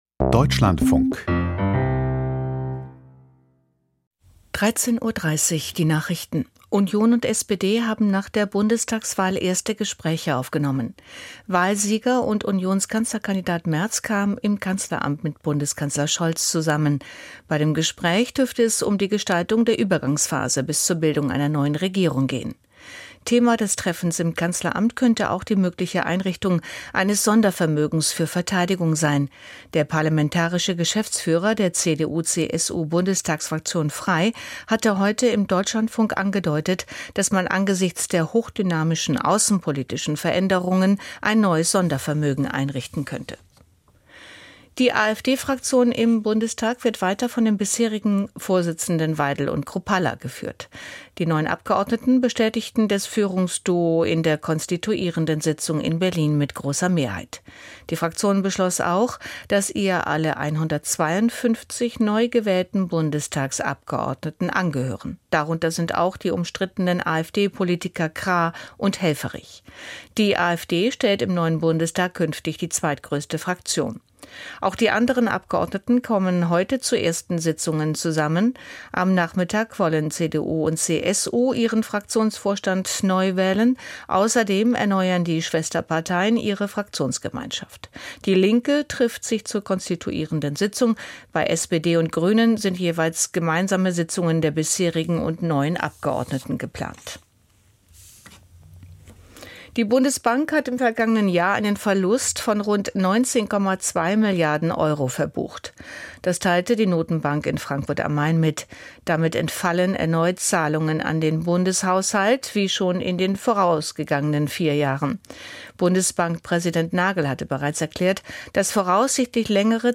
Die Deutschlandfunk-Nachrichten vom 25.02.2025, 13:30 Uhr